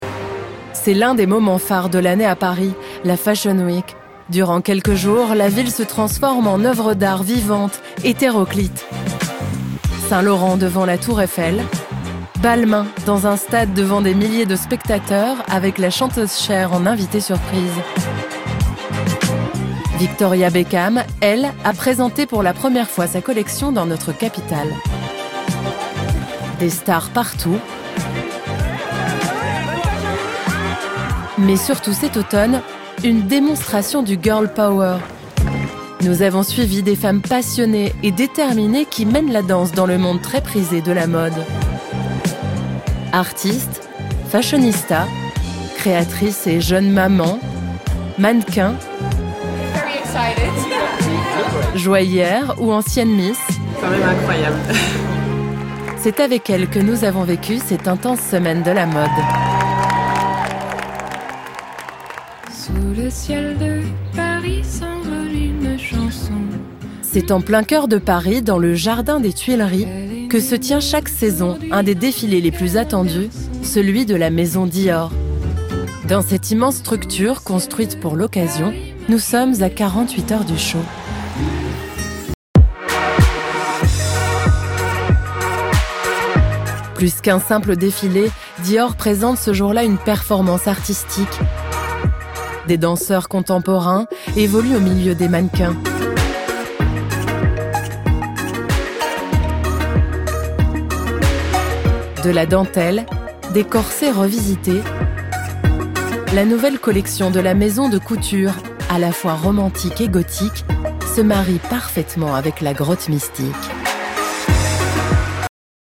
Voix off
7 - 53 ans - Contralto
Accent Belge